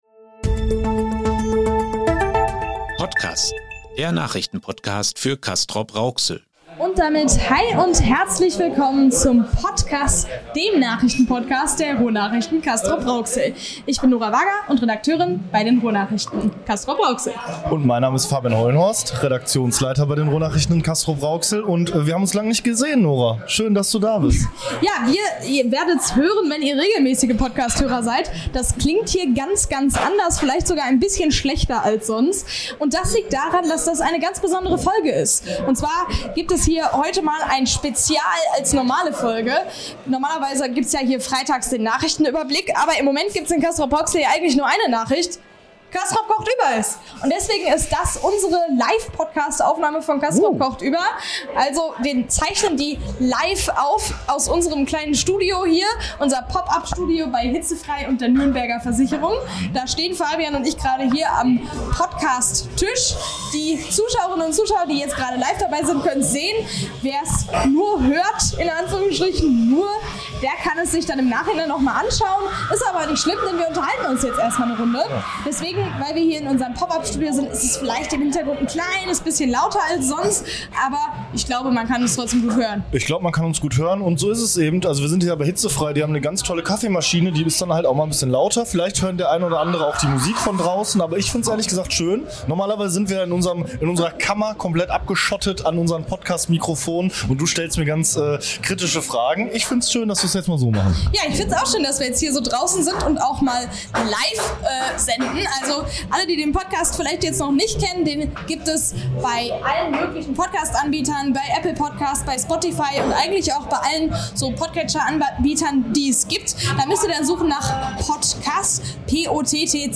Ckü-Spezial: Live bei Castrop kocht über 2023 ~ PottCAS - Der News-Podcast für Castrop-Rauxel Podcast